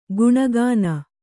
♪ guṇa gāna